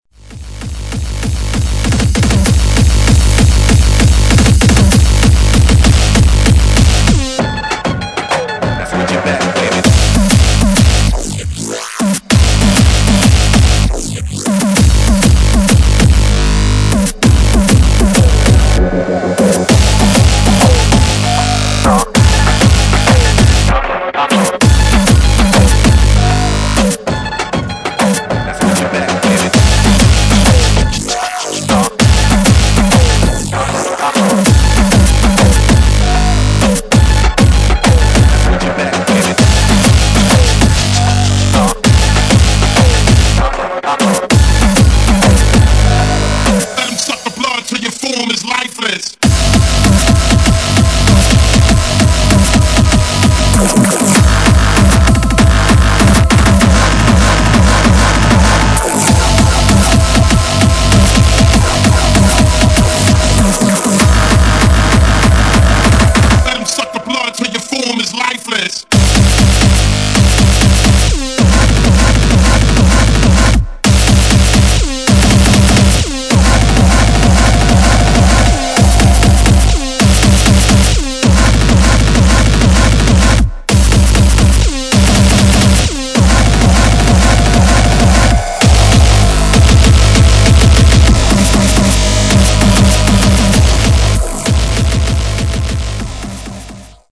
[ HARCORE ]